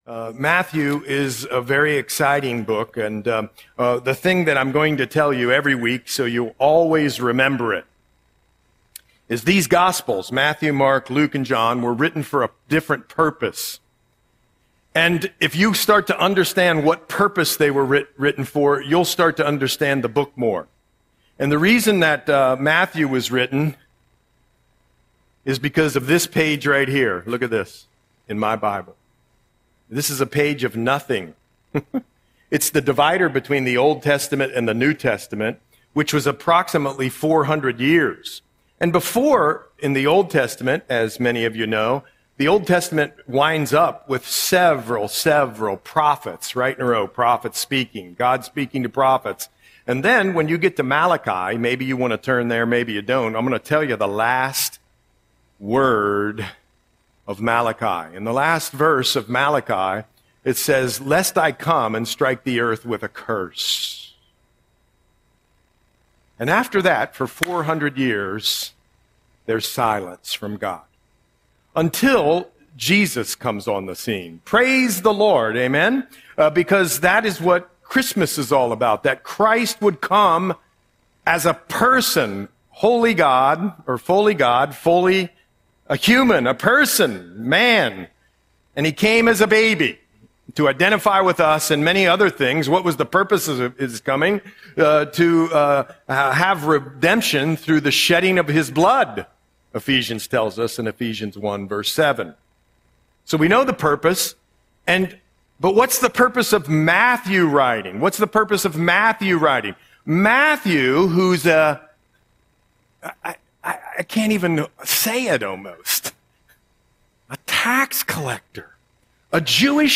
Audio Sermon - November 2, 2025